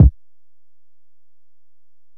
Kick (29).wav